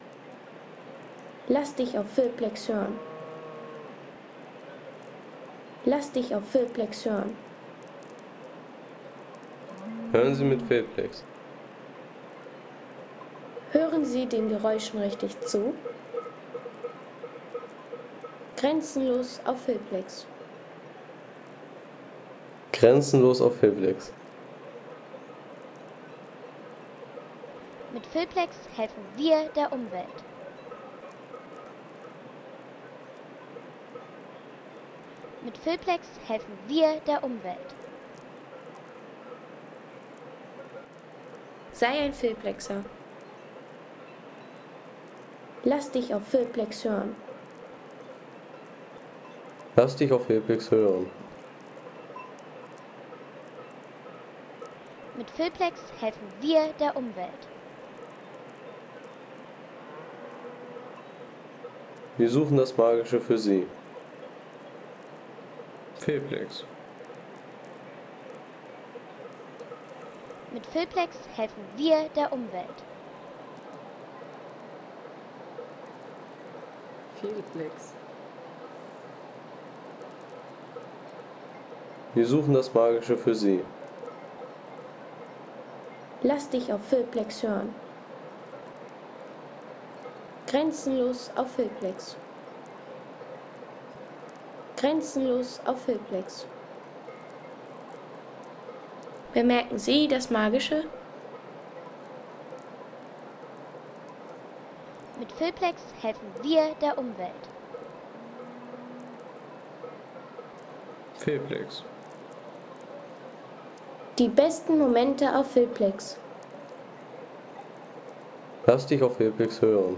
Klänge von Wind und Weide
Landschaft - Berge